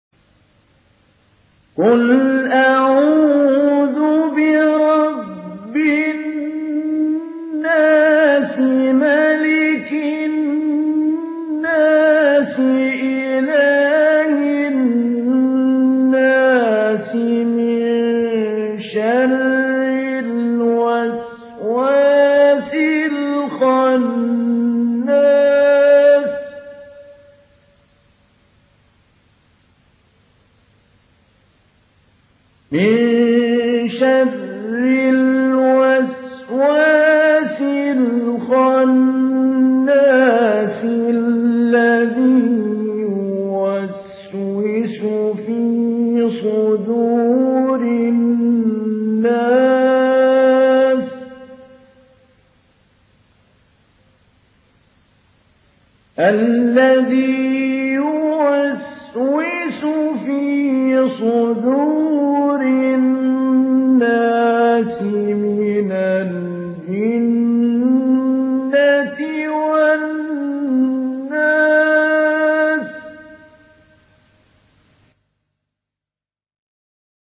Surah আন-নাস MP3 by Mahmoud Ali Albanna Mujawwad in Hafs An Asim narration.